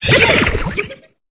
Cri de Vorastérie dans Pokémon Épée et Bouclier.